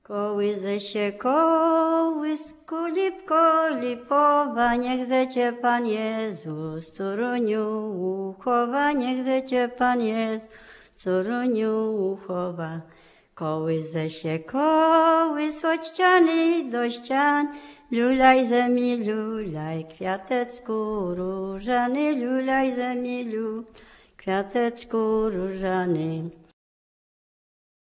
KOŁYSANKI LULLABIES
The lullabies, children's songs, counting-out games and children's play contained on this CD come from the Polish Radio collection.
Half of the recorded tracks are lullabies (tracks 1-30), mostly in recordings from the 1970s, 1980s and 1990s, performed by singers born in the early 20th century.[...]